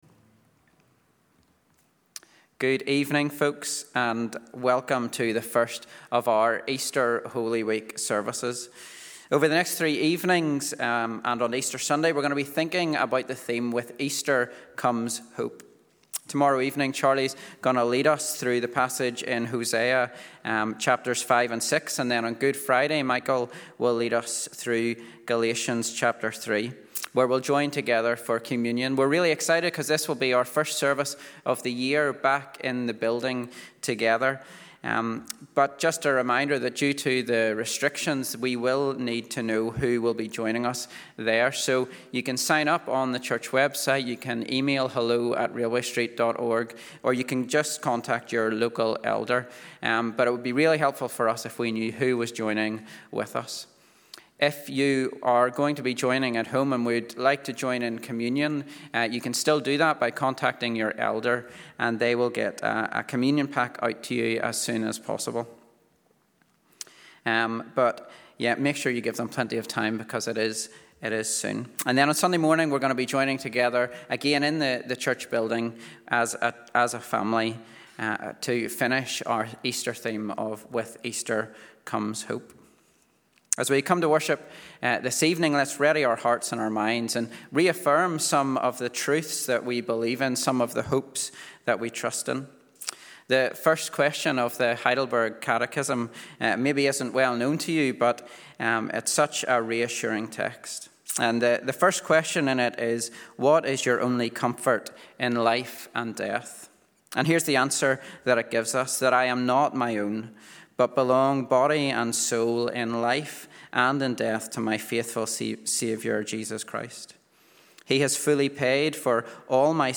This evening is the first of our Holy Week services based on the theme 'With Easter Comes Hope'. Join us as discover how hope is promised in Isaiah and completed in Christ.